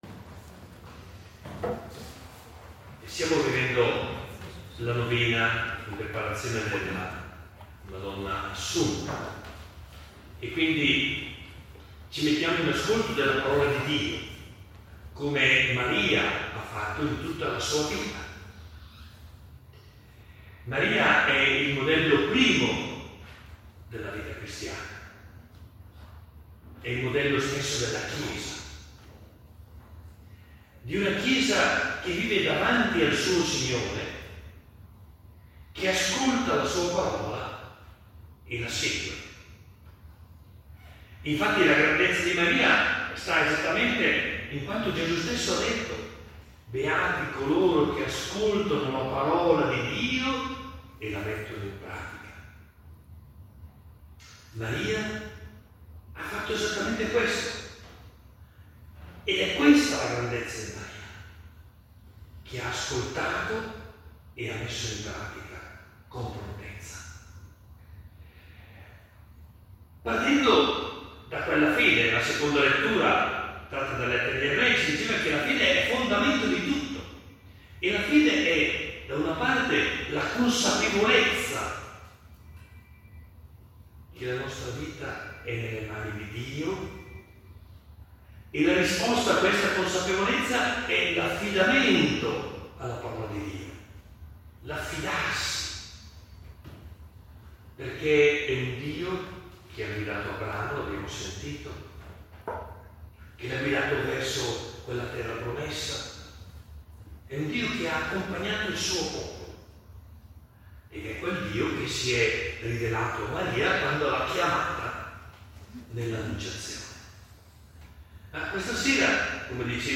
Celebrazione del 9 agosto 2025 – Monsignor Carlo Bresciani riflessione: Maria, segno della gratuità e della riconoscenza